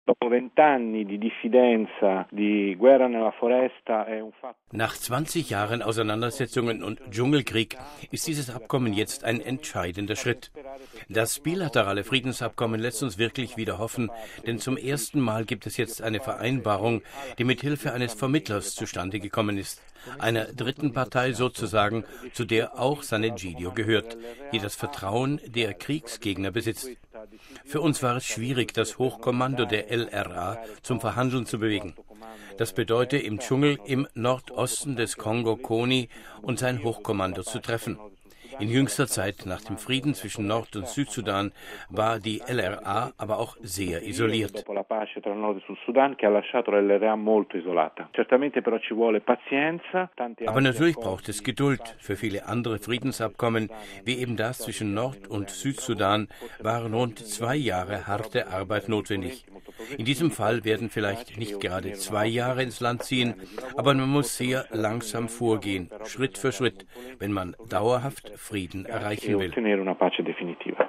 Wir haben mit dem Afrika-Experten von Sant Egidio gesprochen, Mario Giro: